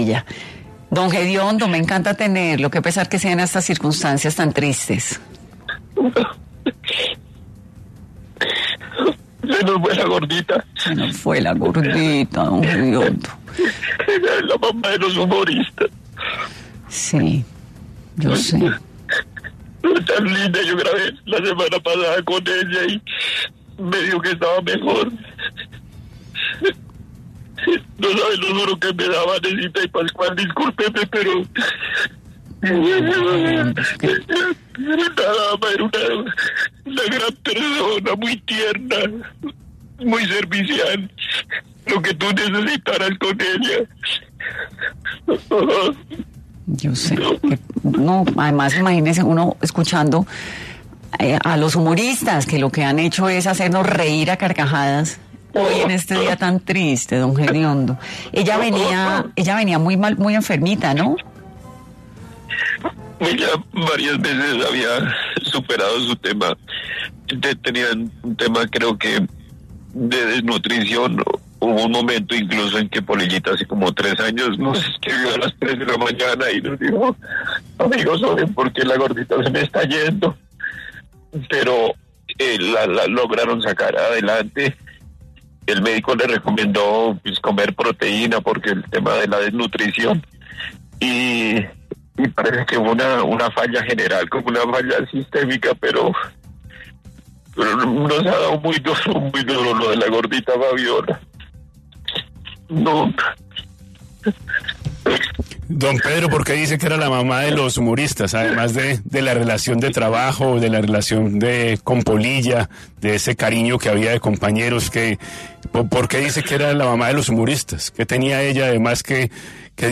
Don Jediondo rompe en llanto tras la muerte de la Gorda Fabiola: Me dijo que estaba mejor
El humorista Pedro González estuvo en 10AM tras el fallecimiento de la gorda Fabiola, dijo que “era la mamá de los humoristas”